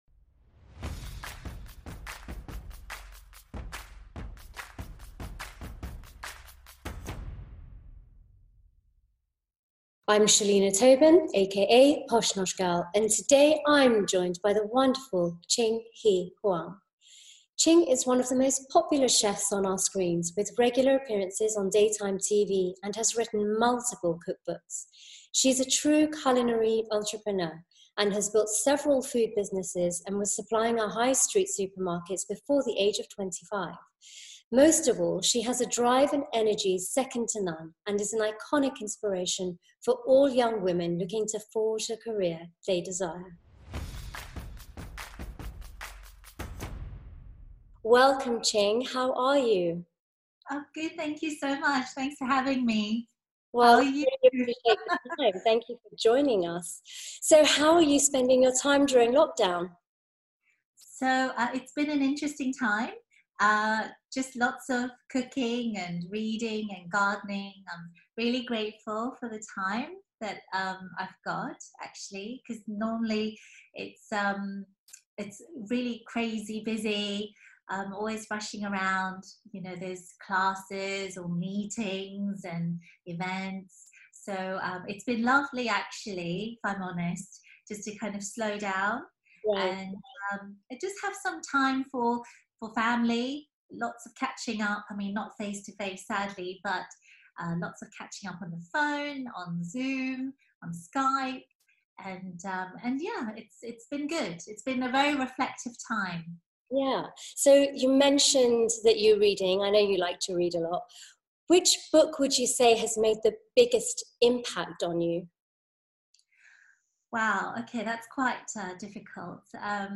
TV Chef & Food Writer Ching shares her incredibly inspiring story about her humble beginnings and working her way into TV.